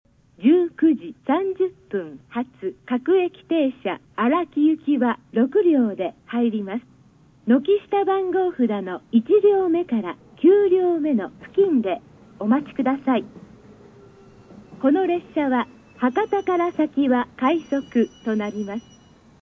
スピーカー： １、２、４、５番線、不明　　３番線、カンノ製作所　　６・７番線、TOA（放送なし）
音質：D〜E
３番のりば 接近前放送 普通・荒木　(95KB/19秒)